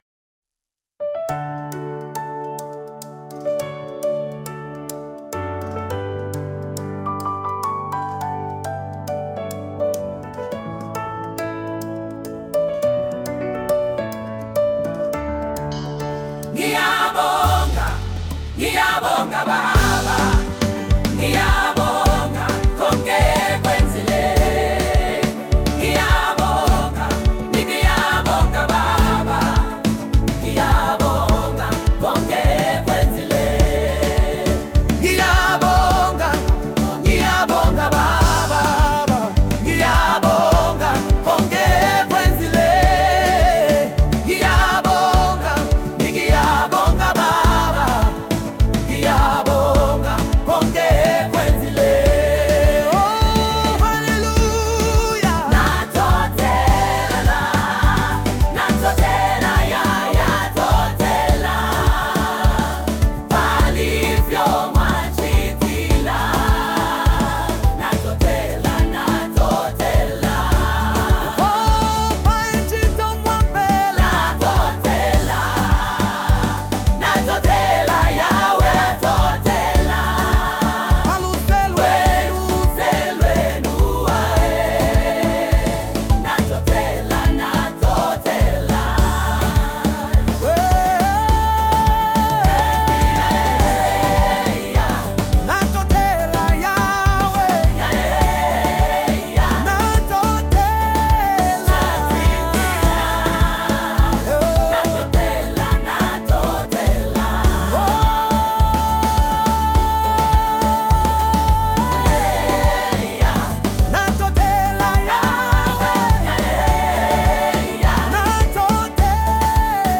gospel